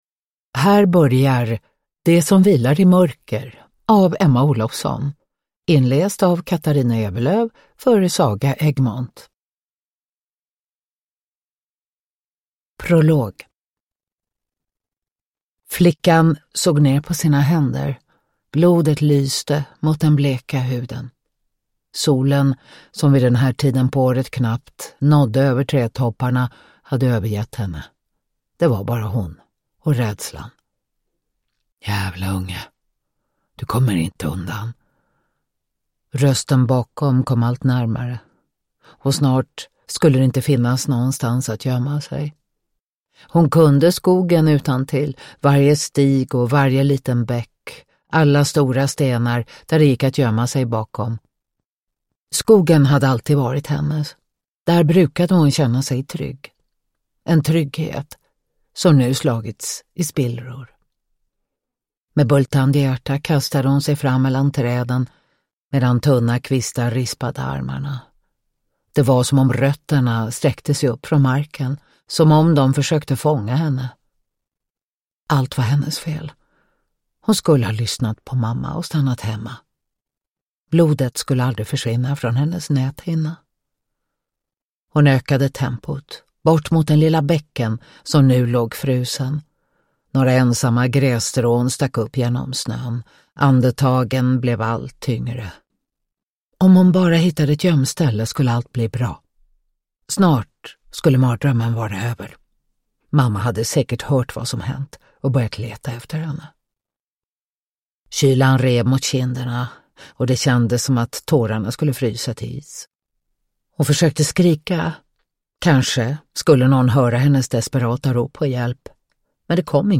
Det som vilar i mörker – Ljudbok
Uppläsare: Katarina Ewerlöf